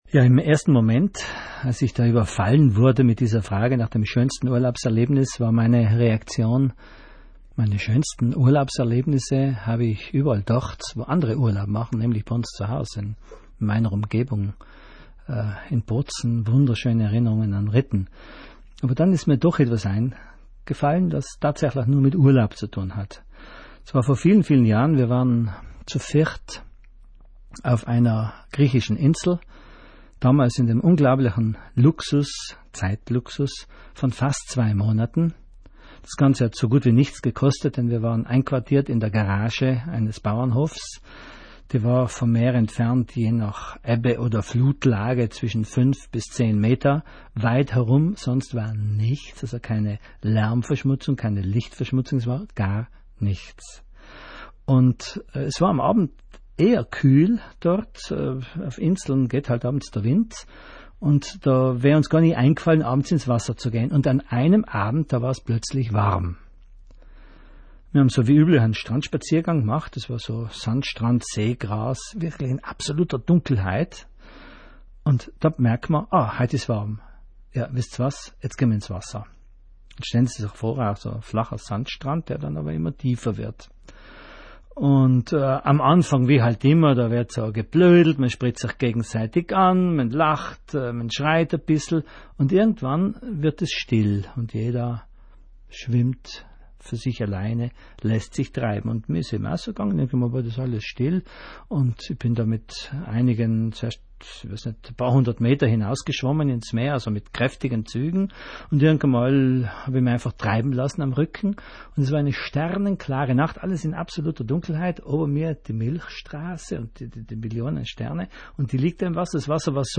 Interview der RAI: eine nächtliche Reise durch den Kosmos.